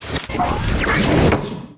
accretia_guardtower_takeup.wav